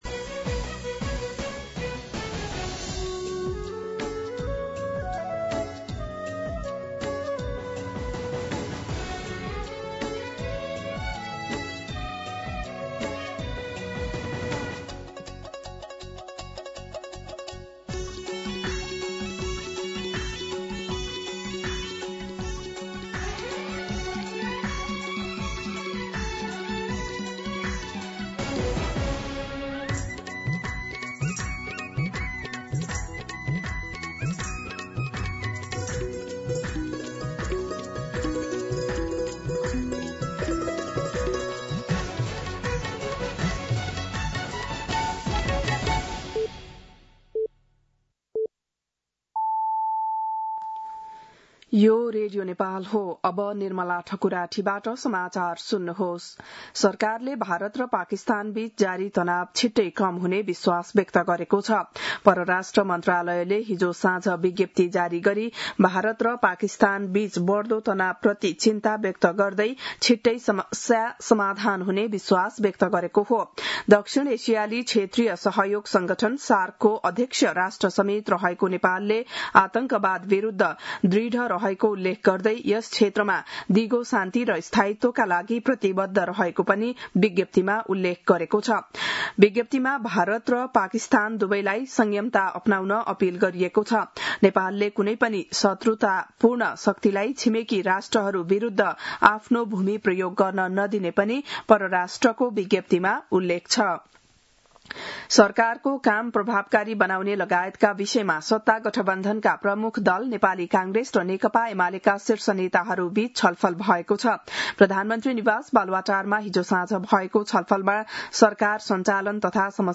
बिहान ११ बजेको नेपाली समाचार : २६ वैशाख , २०८२